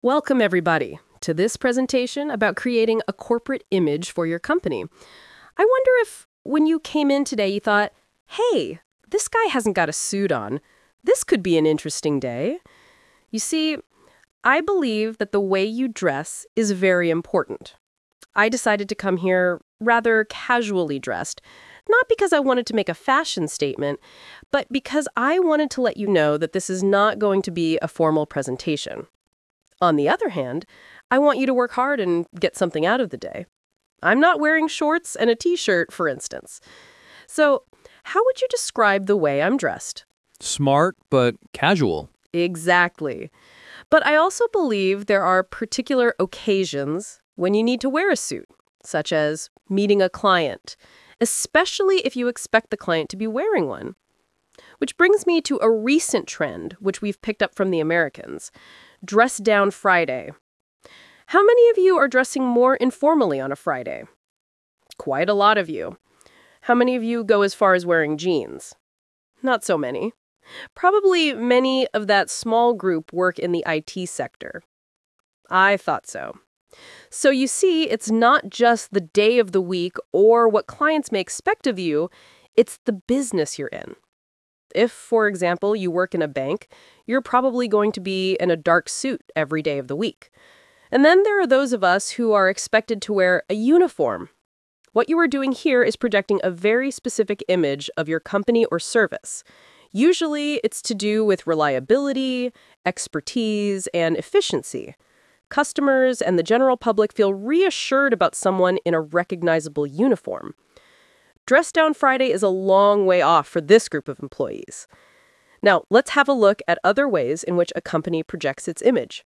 Talk/Lecture 1: You will hear a presentation about fashion at work.